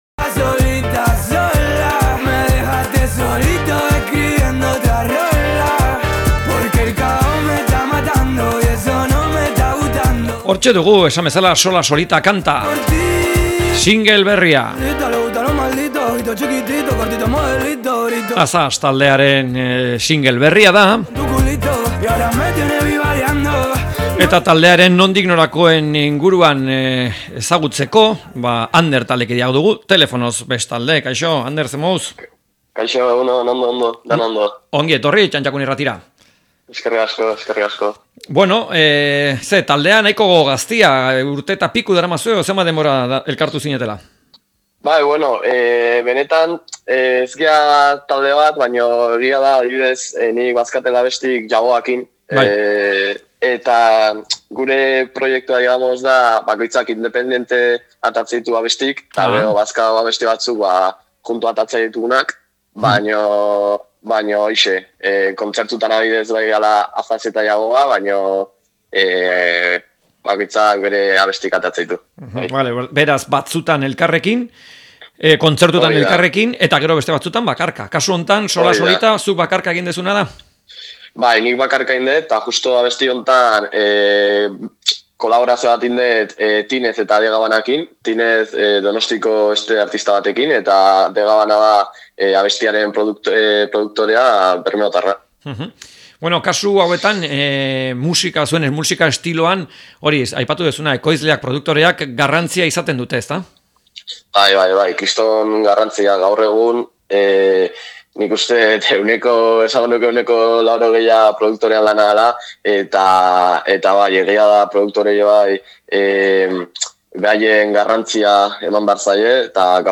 Elkarrizketak